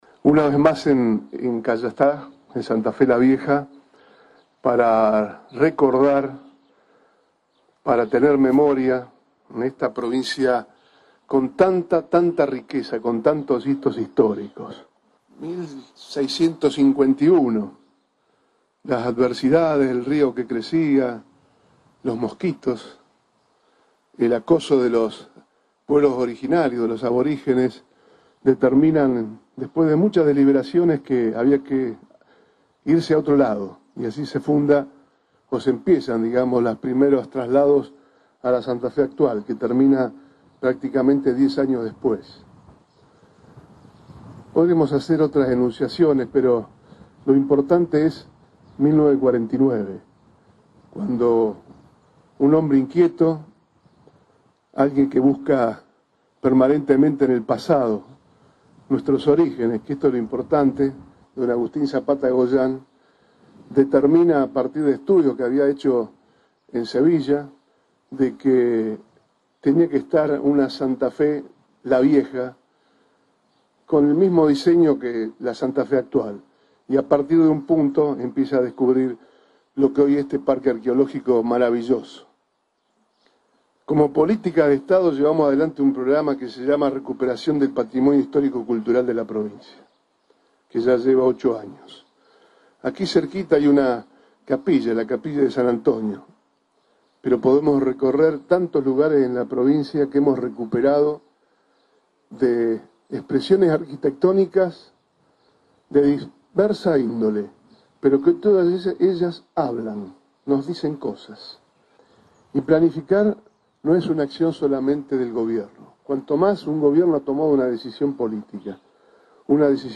El gobernador Antonio Bonfatti encabezó el acto conmemorativo del 442º aniversario de la fundación de la ciudad de Santa Fe, en una ceremonia que se llevó a cabo en el Parque Arqueológico "Ruinas de Santa Fe la Vieja", en la localidad de Cayastá.
Palabras de Antonio Bonfatti.